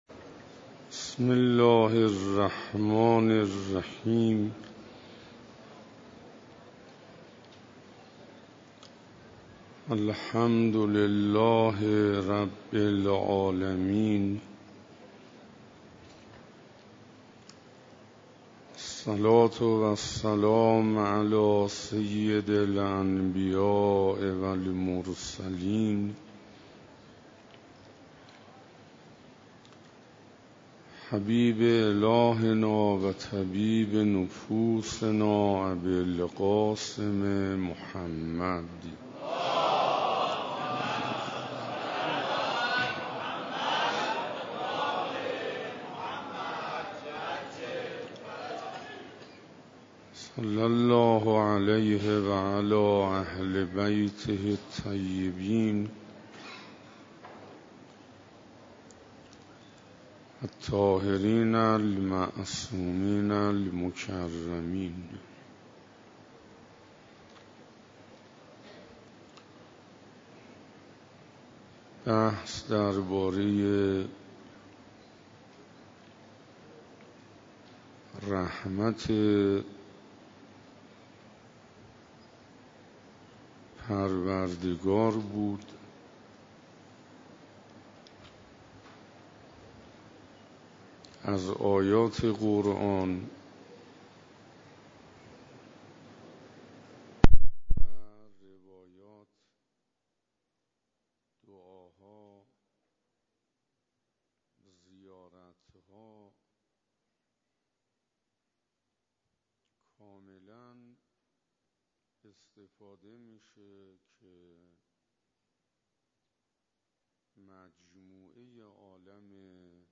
روز 4 رمضان97 - مسجد امیر علیه السلام - رمضان